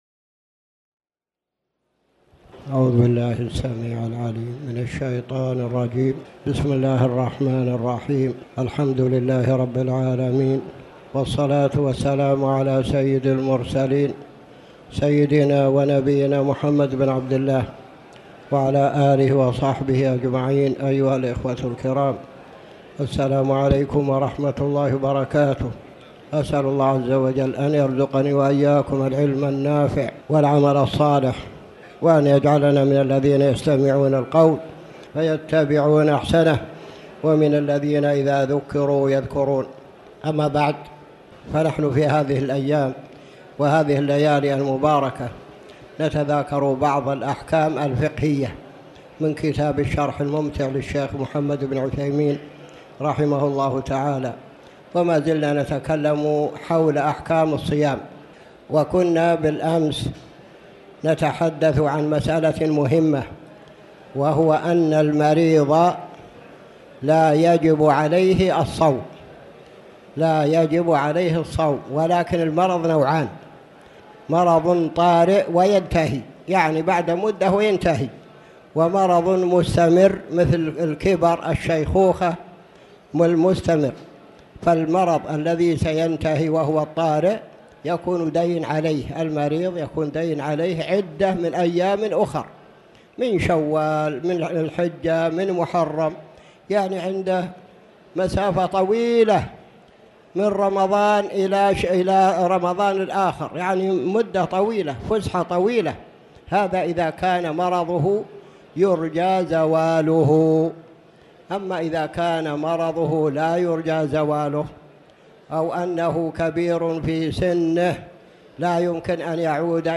تاريخ النشر ١٤ جمادى الأولى ١٤٣٩ هـ المكان: المسجد الحرام الشيخ